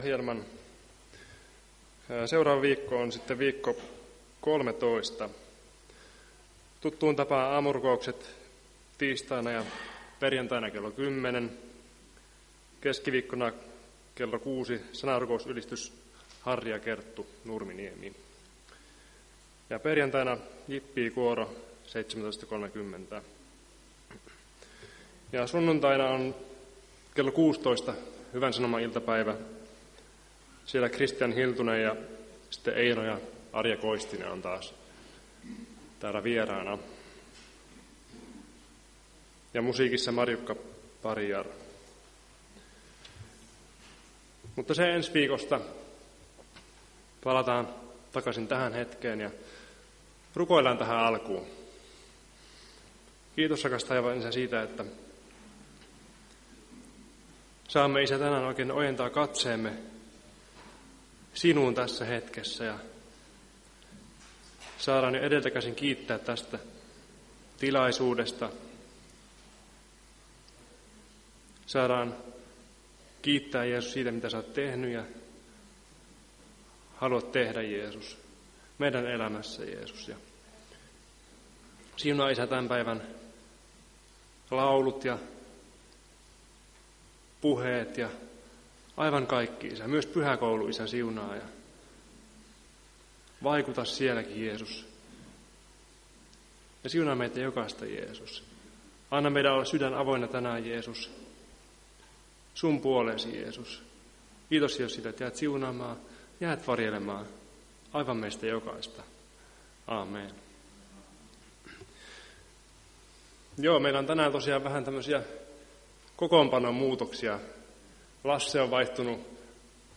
Päiväkokous 23.3.2025